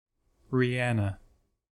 ree-AN;[3][4][n 1] born February 20, 1988) is a Barbadian singer, businesswoman, and actress.
En-us-rihanna.ogg.mp3